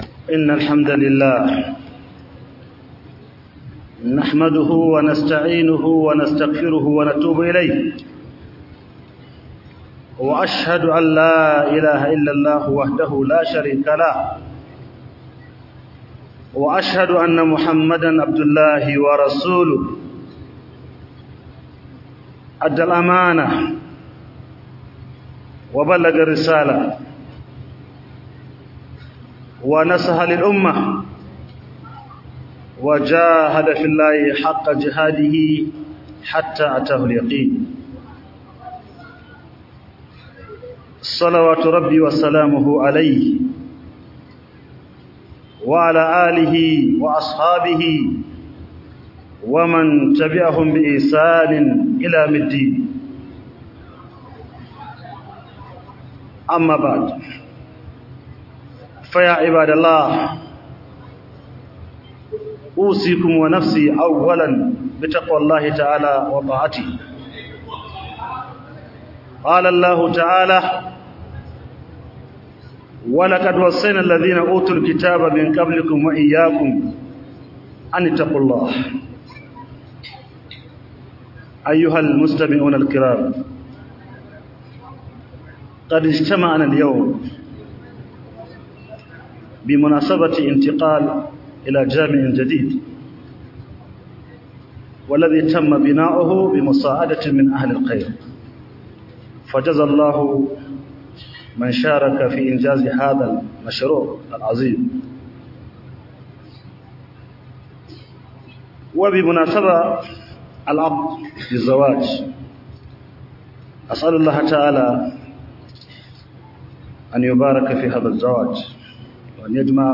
Khuduba Daga Masallacin Umar Bin Khaddab Bauchi 2025